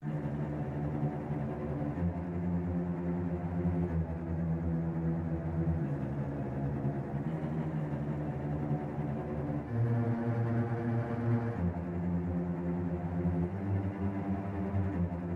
标签： 125 bpm Cinematic Loops Strings Loops 2.58 MB wav Key : D
声道立体声